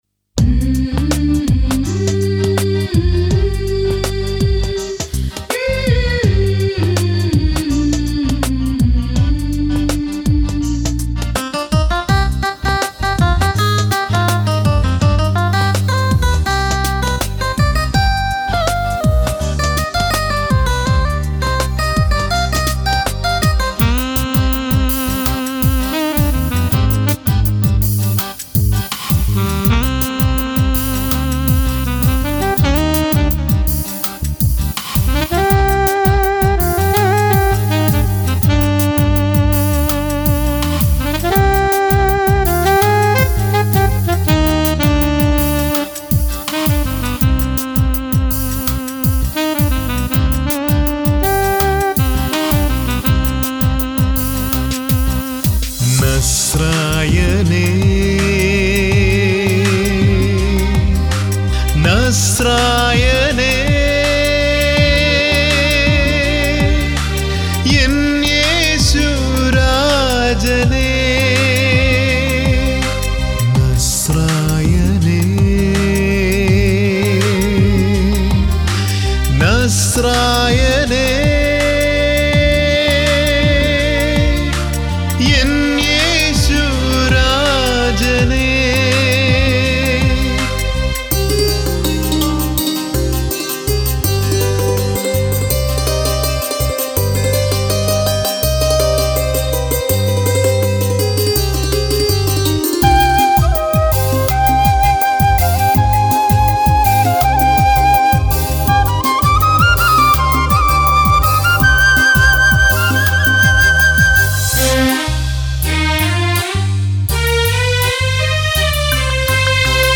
Keyboard Sequence
Rhythm Sequence
Flute
Tabala & Percussion